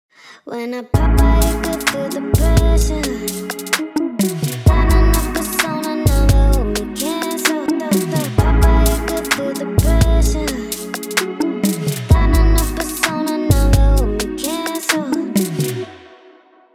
If you’re tired of thin and lifeless vocals, the Vocal Doubler is the tool for you.
Before Vocal Doubler
Vocal_doubler_audio_BEFORE.wav